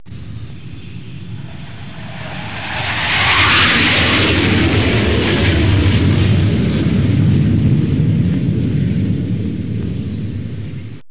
دانلود آهنگ طیاره 49 از افکت صوتی حمل و نقل
جلوه های صوتی